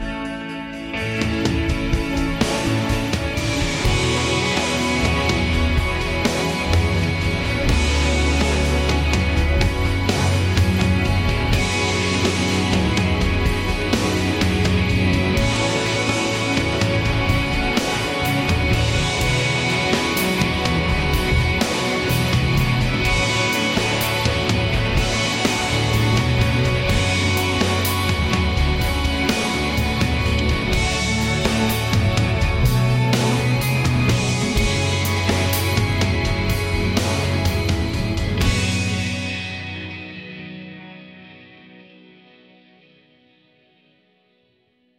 Prompt : Post-Rock, Guitars, Drum Kit, Bass, Strings, Euphoric, Up-Lifting, Moody, Flowing, Raw, Epic, Sentimental, 125 BPM